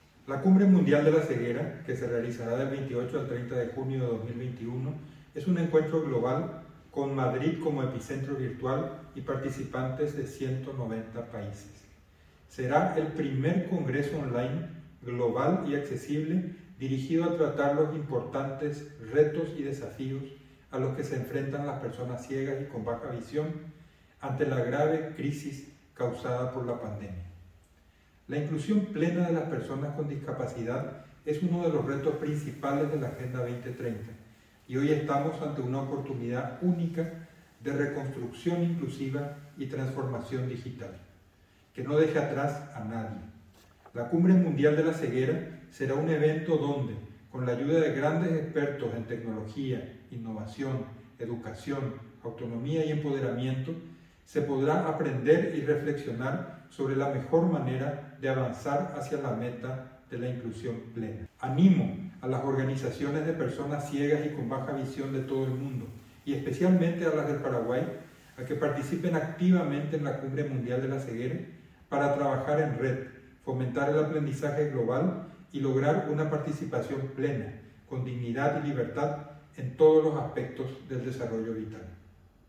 Ricardo Scavone, embajador de Paraguay en España,